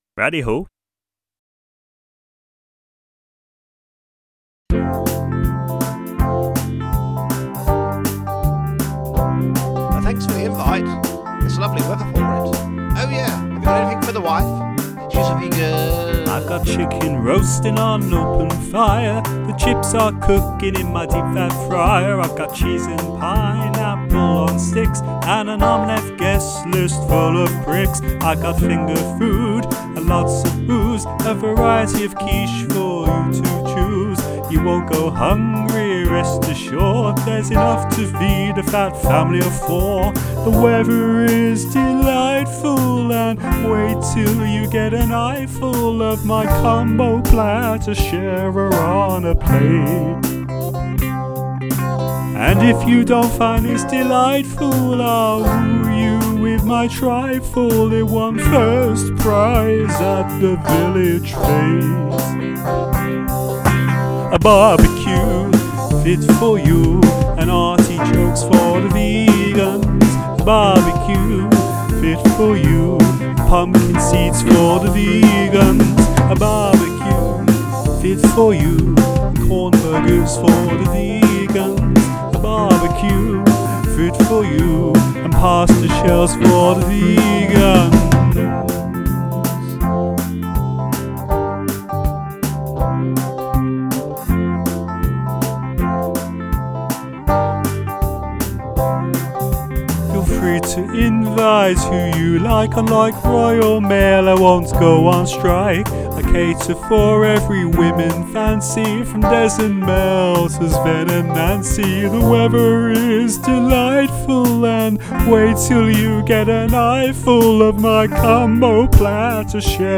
It was very confusing for my dazzling guitar solo.